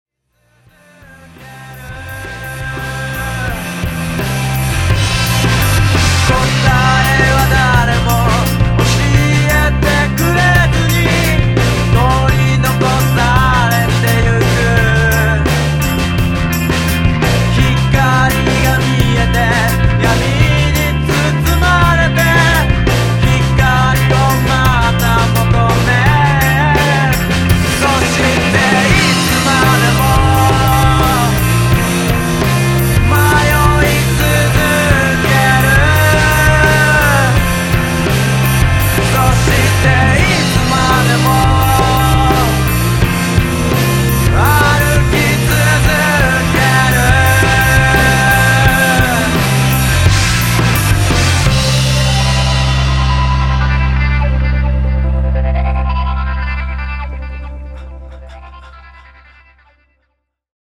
U.K.の匂いがするギターポップバンド。心のひだをめくって精神の奥深いところを歌っているのだけど、暗くなりがちなところを、サラッと明るく歌っているのが気持ちいい。ボーカルレベルがもう少し大きいといいんですが。
おサイケなフィーリングがたまらないバンドですね。